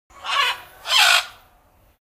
bird_gull.ogg